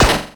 snd_enemy_bullet_shot.wav